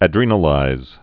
(ə-drēnə-līz, ə-drĕnə-)